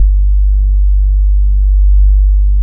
70 MOOG BASS.wav